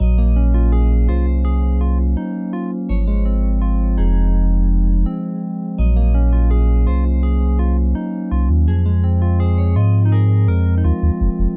Forever Gone Rhode KEYz With Bass 83bpm
Tag: 83 bpm RnB Loops Synth Loops 1.95 MB wav Key : Unknown